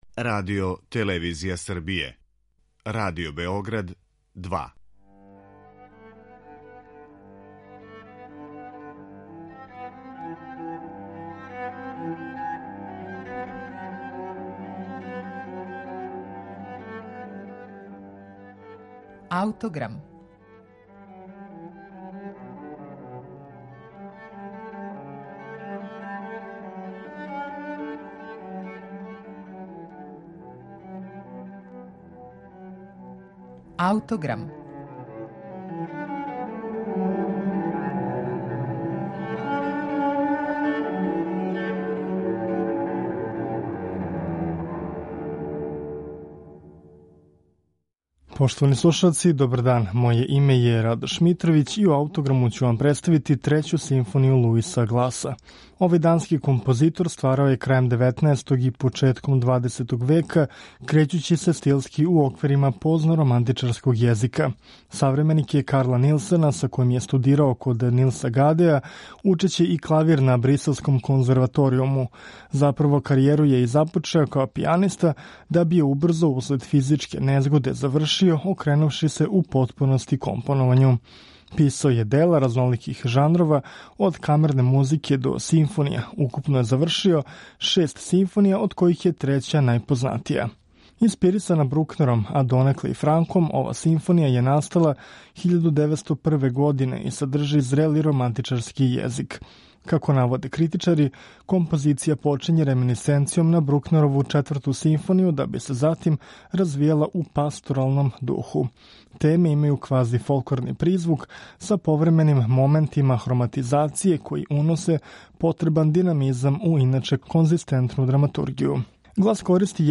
крећући се стилски у оквирима позноромантичарског језика.